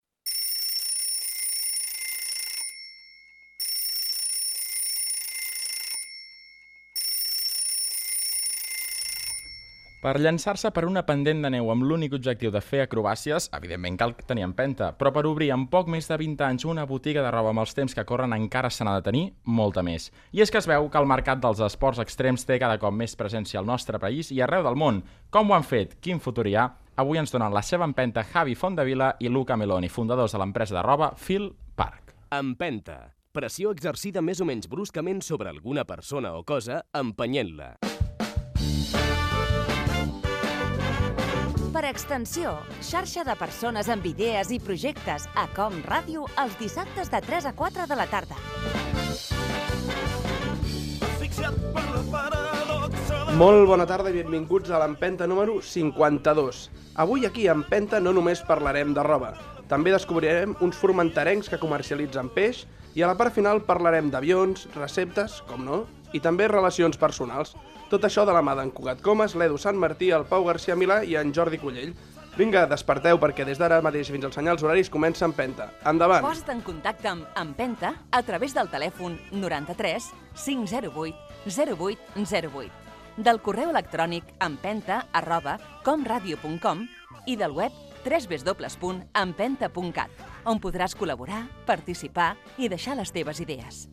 Presentació, careta, equip, telèfon i formes de contcte amb el programa
FM